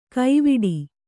♪ kaiviḍi